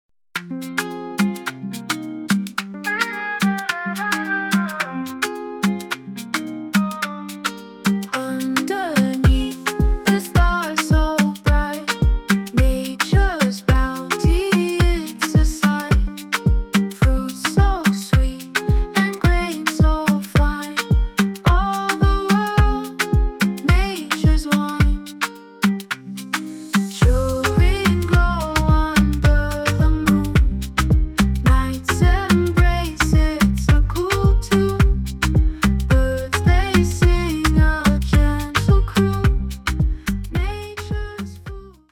Short version of the song, full version after purchase.
An incredible Afrobeats song, creative and inspiring.